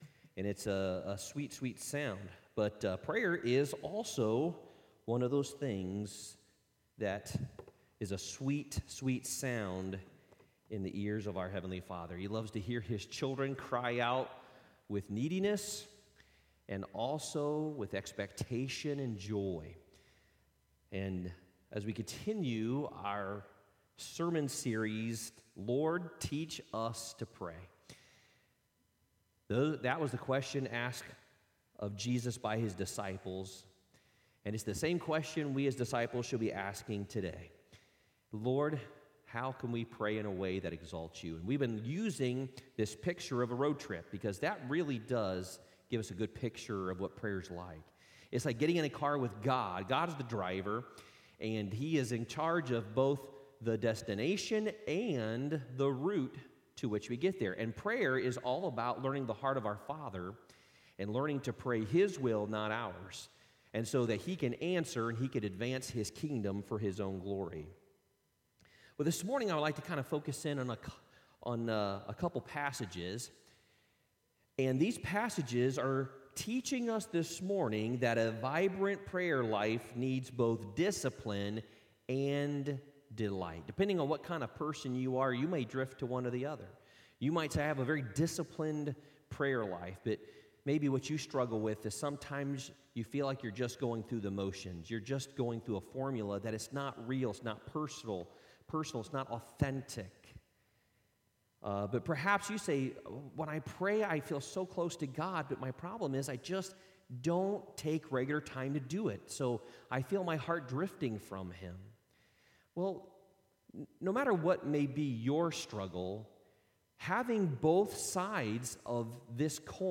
Worship Service 05/16/2021